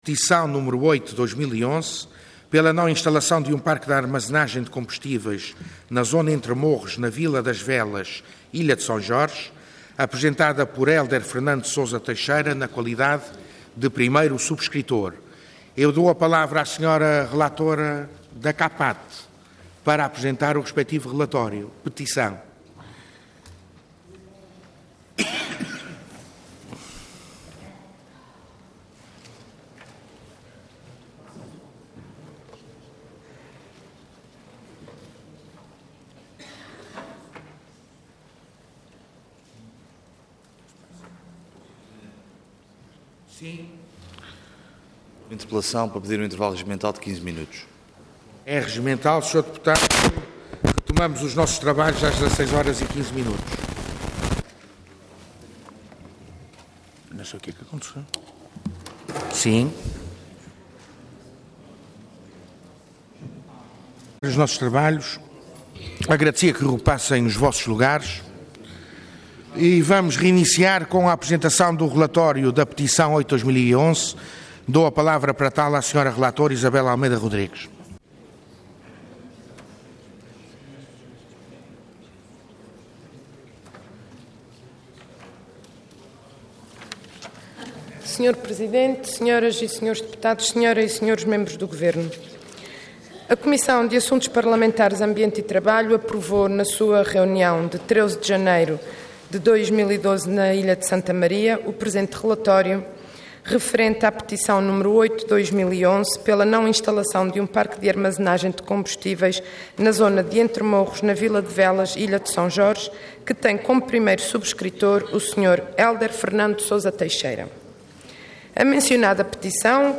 Intervenção Petição Orador Isabel Rodrigues Cargo Relatora Entidade Comissão de Assuntos Parlamentares Ambiente e Trabalho